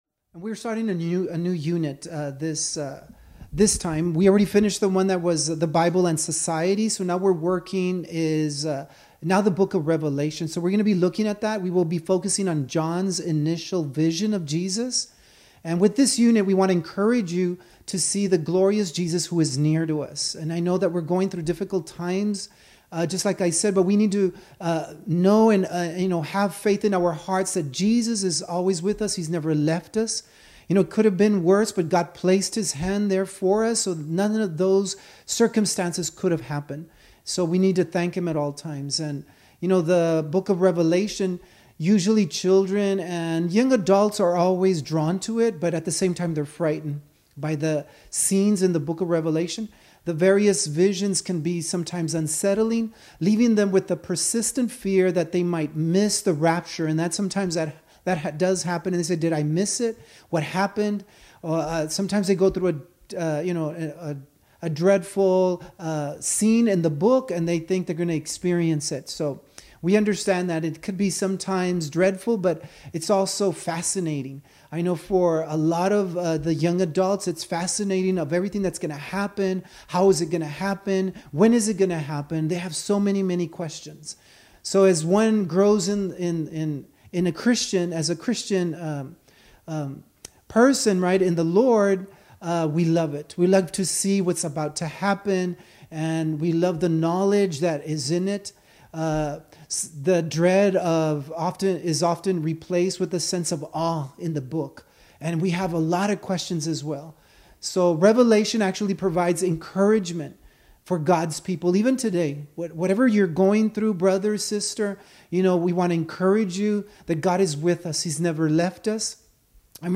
SERMONS - Rock of Ages AOG